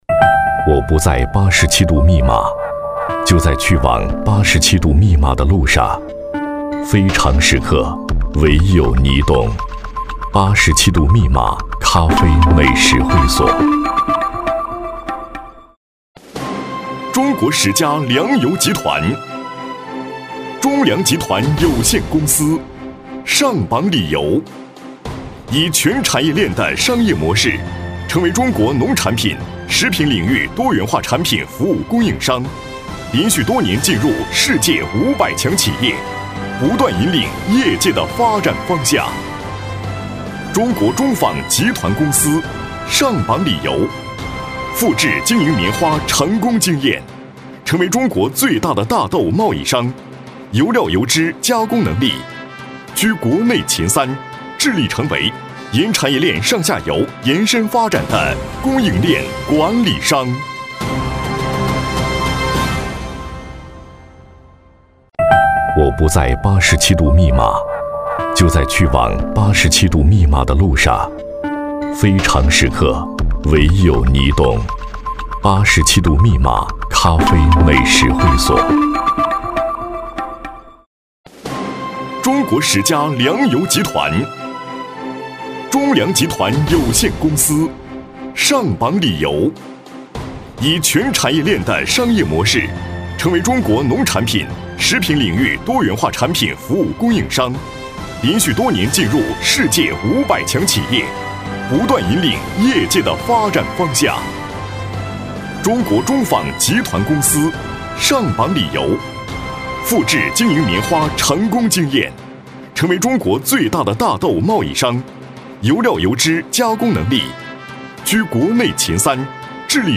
国语青年大气浑厚磁性 、沉稳 、积极向上 、男广告 、400元/条男S354 国语 男声 广告-名景成-地产广告-大气浑厚 大气浑厚磁性|沉稳|积极向上